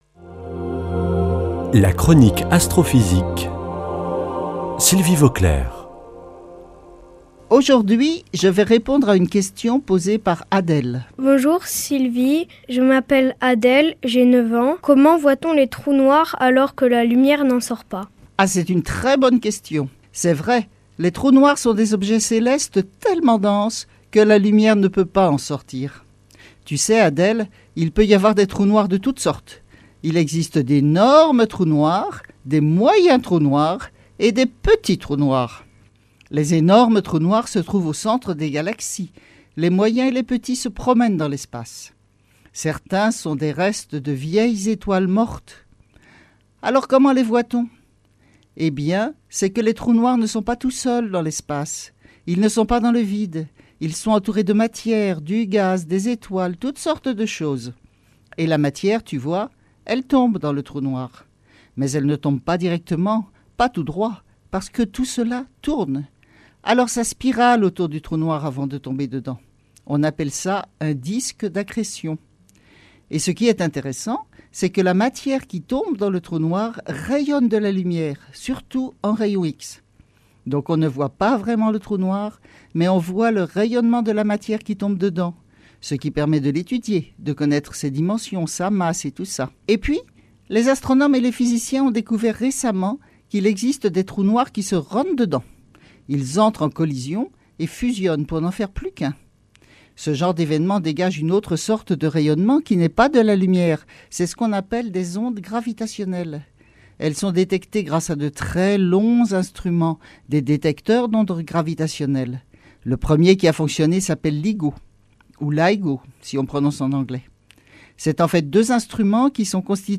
Chronique Astrophysique du 19 nov.